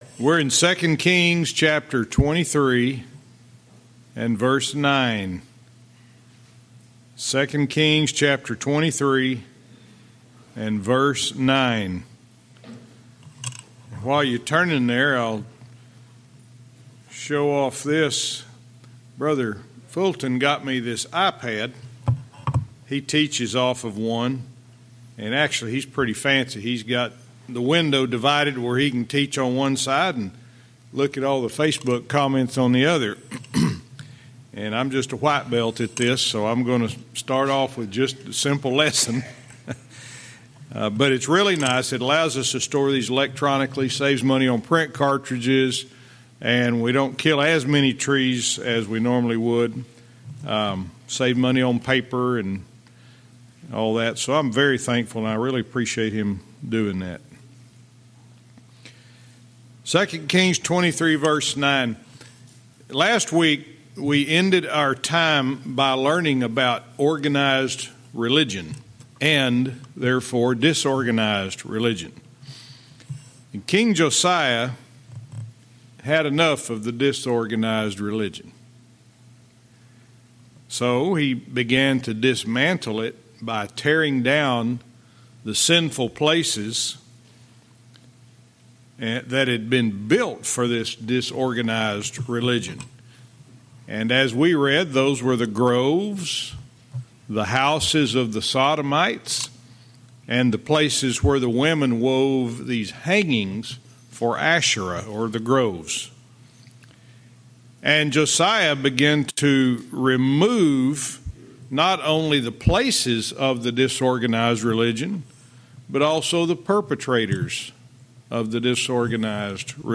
Verse by verse teaching - 2 Kings 23:9-10